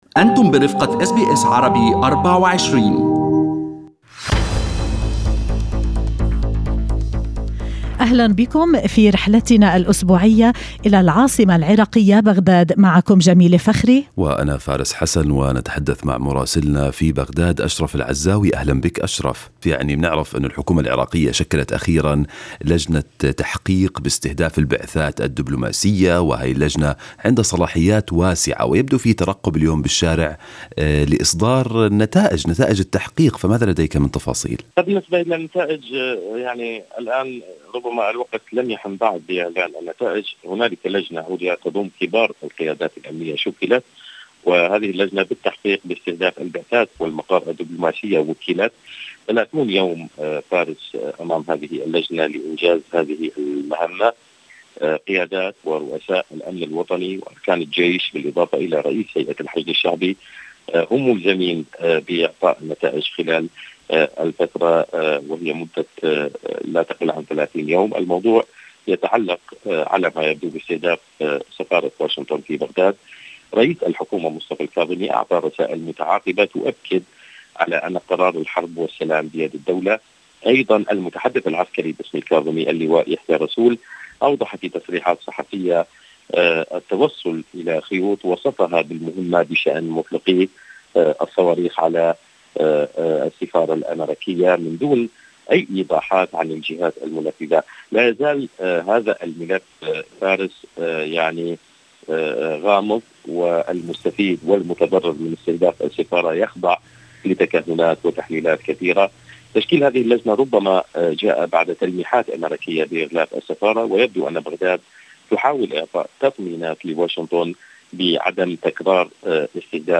من مراسلينا: أخبار العراق في أسبوع 9/10/2020